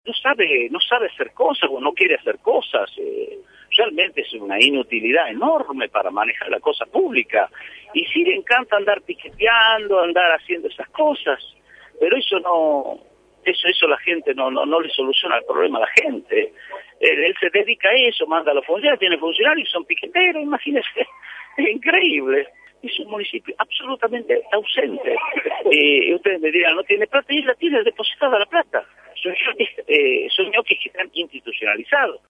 Luis Beder Herrera, gobernador, por Radio La Red Ismael Bordagaray, intendente de Famatina, por Radio La Red